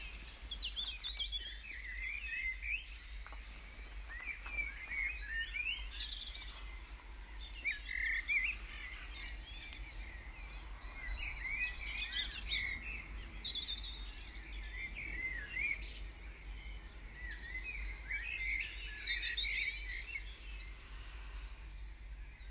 In terms of birds, the female chooses the male with the loudest song, so they are singing for all they are worth. If you listen carefully, there is a cuckoo on the background here.
Dawn-chorus-2-20.m4a